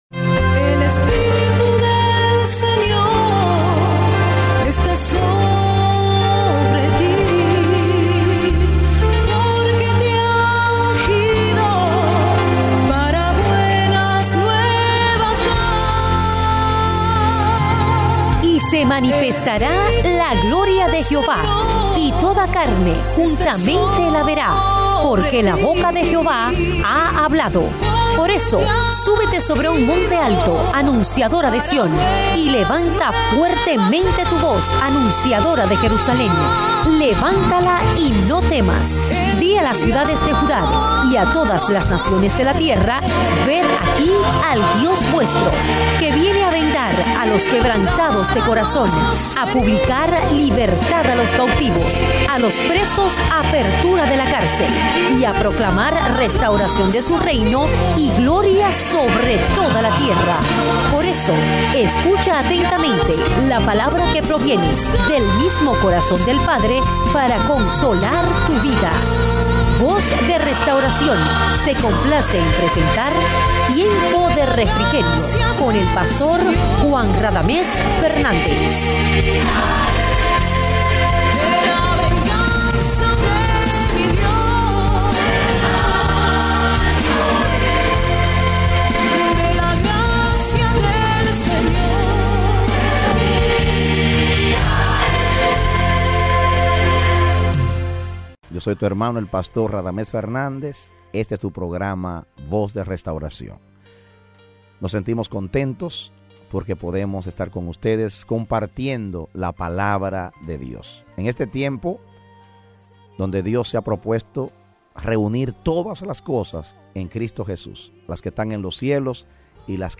A mensaje from the serie "Programas Radiales."